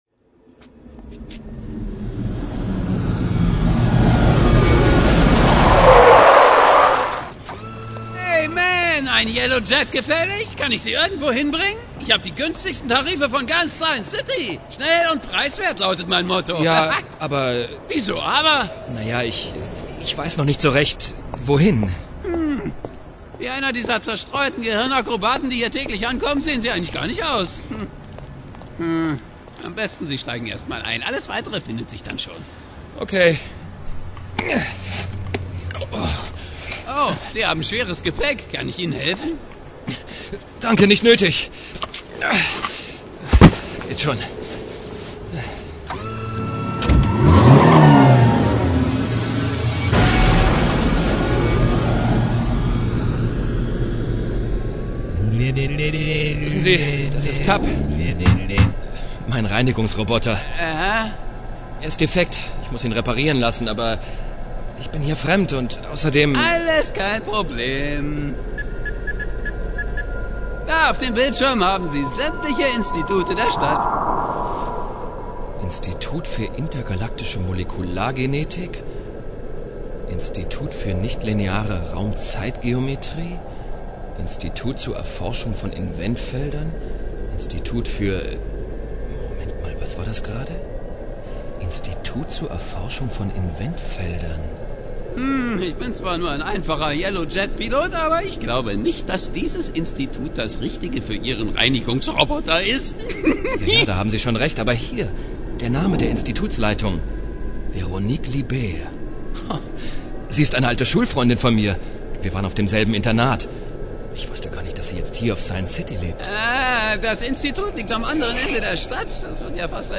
Das Hörspiel besticht durch eine kurzweilige Story, deren Sprechrollen perfekt besetzt sind.
So sind beispielsweise die Toneffekte und die Musik zur Unterstreichung der Atmosphäre perfekt gelungen und machen selbst auf einer hochwertigen Tonanlage sehr viel Spaß.
Hörprobe (mit freundlicher Genehmigung von Polaris Hörspiele, Berlin)